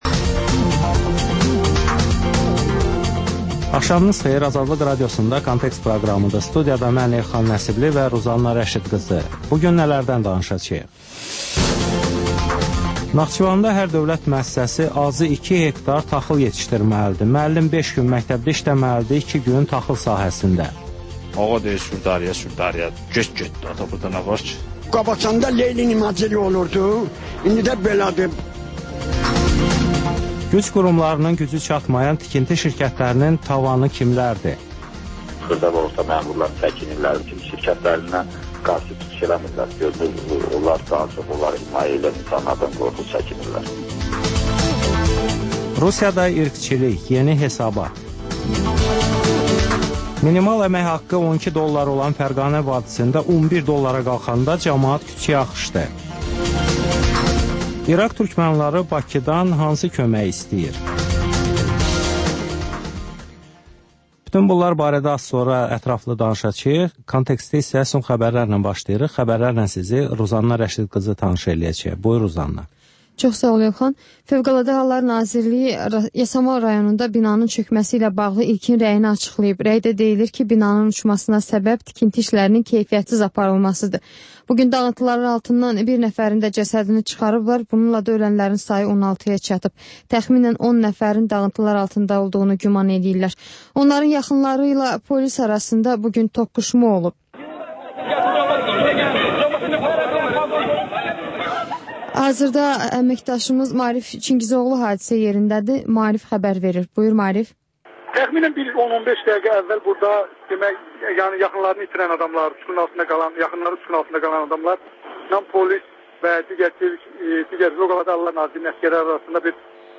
Xəbərlər, müsahibələr, hadisələrin müzakirəsi, təhlillər, sonda ŞƏFFAFLIQ: Korrupsiya haqqında xüsusi veriliş.